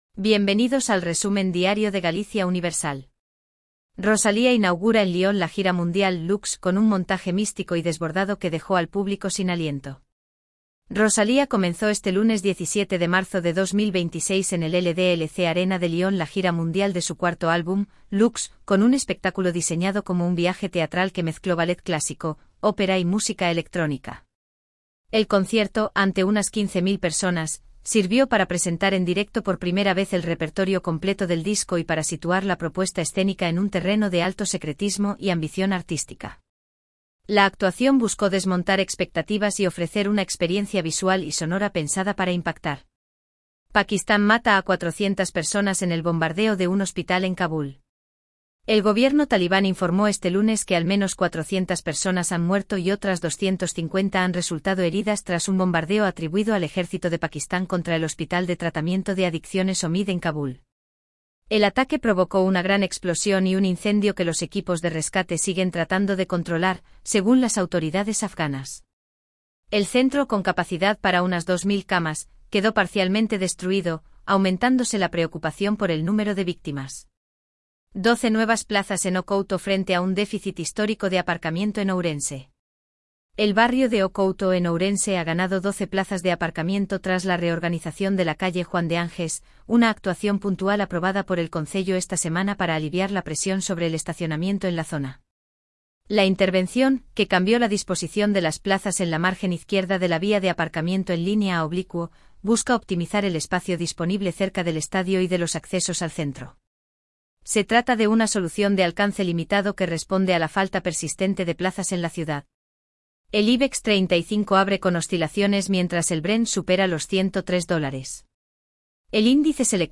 Voz: Elvira · Generado automáticamente · 5 noticias